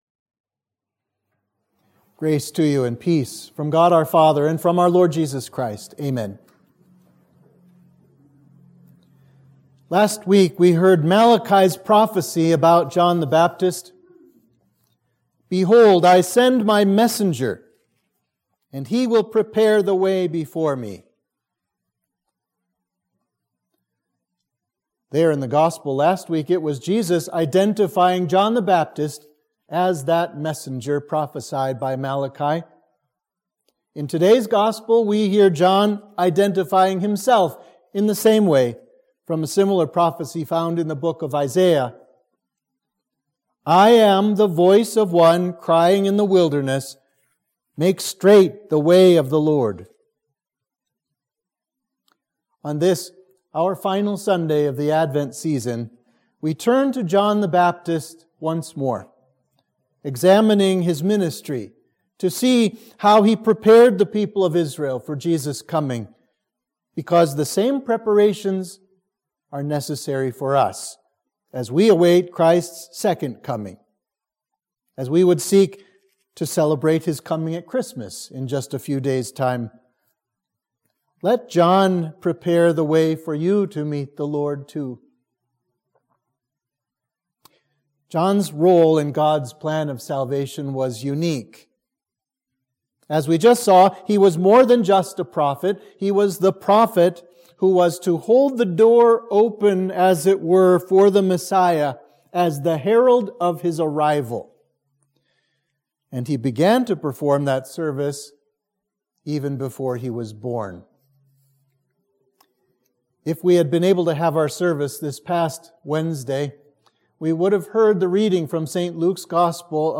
Sermon for Advent 4